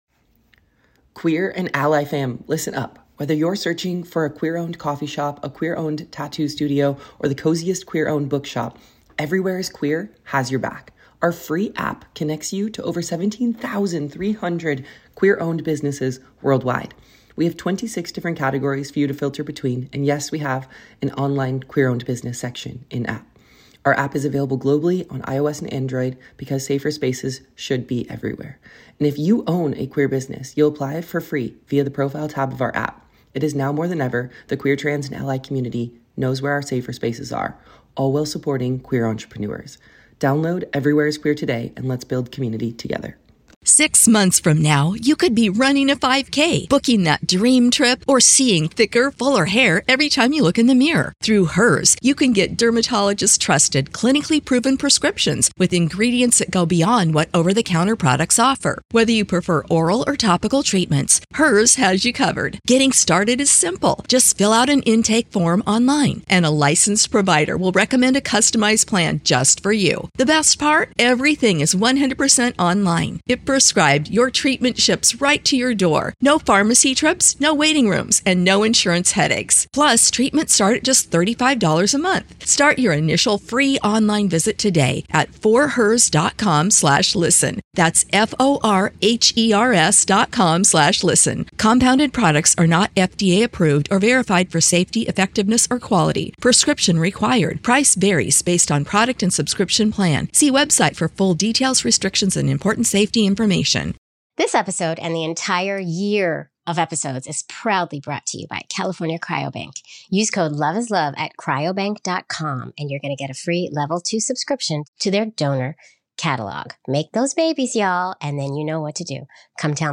midwives sharing their personal journeys. Explore the intricacies of donor selection, legal agreements, and family integration.